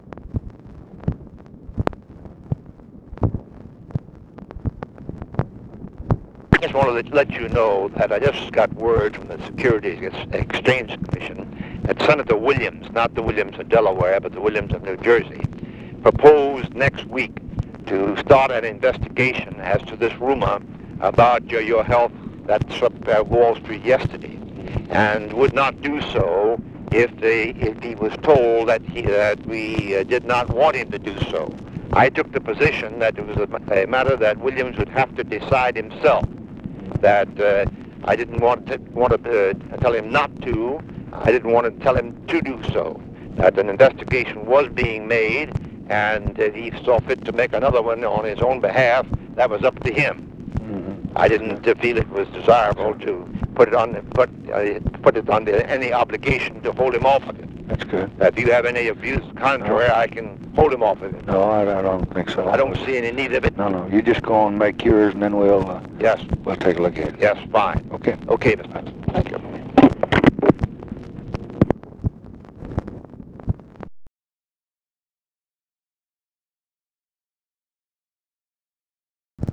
Conversation with J. EDGAR HOOVER, June 10, 1965
Secret White House Tapes